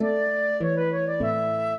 flute-harp
minuet3-5.wav